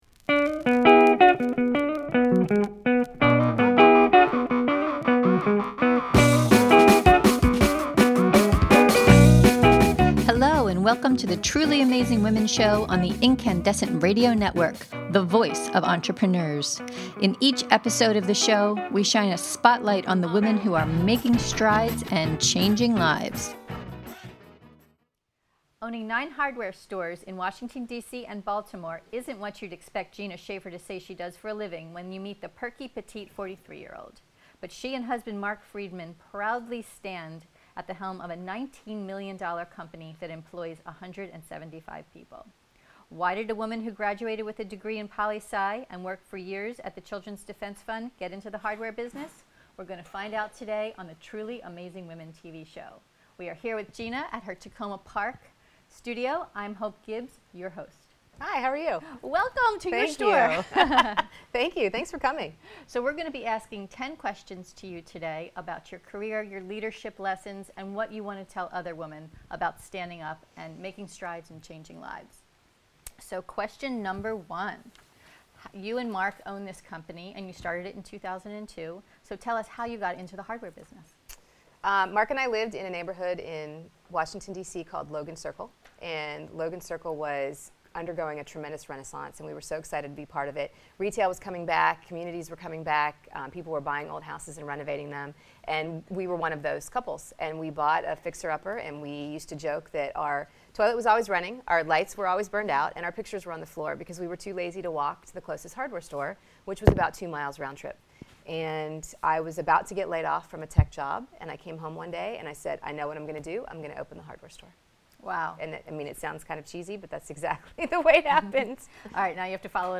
fascinating monthly podcast interviews